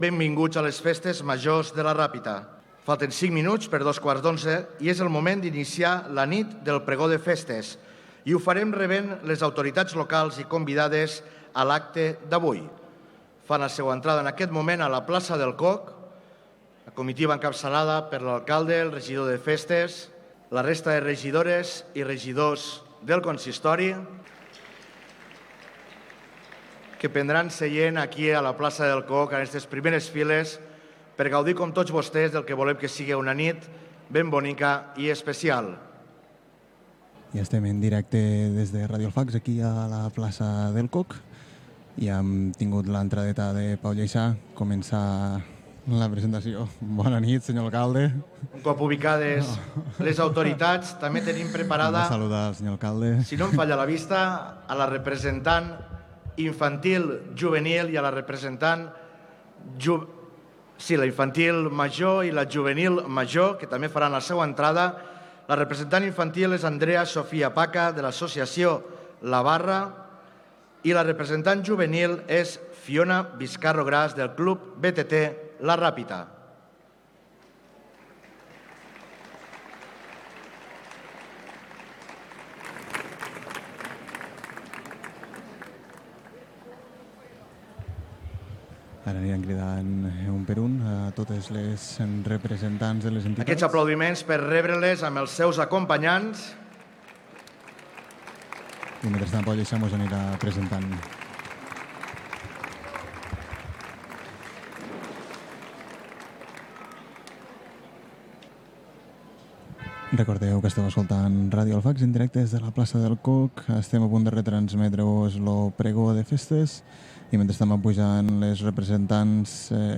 Transmissió des de la Plaça del Cóc de La Ràpita de l'acte del pregó de festa majó de la Ràpita
Informatiu